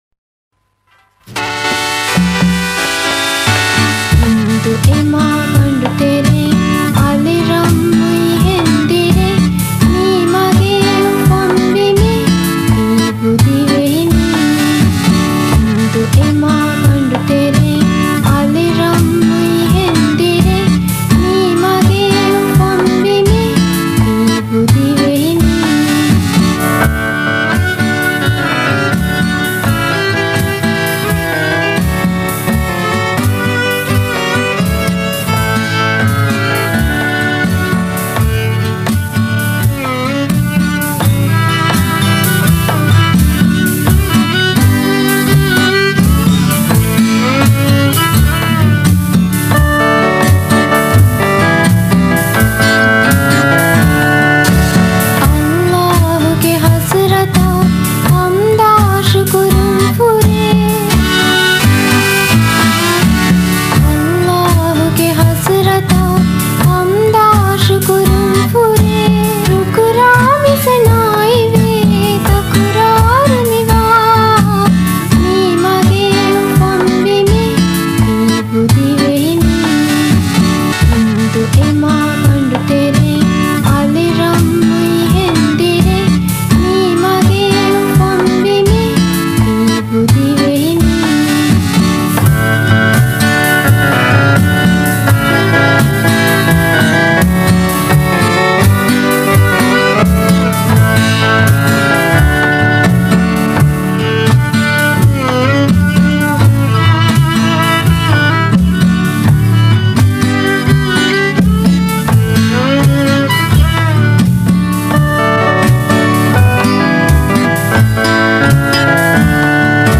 ކޮންމެ ބީޓެއް ތިންބަޔަށް ބެހެއެވެ.
ދިވެހި ލަވަ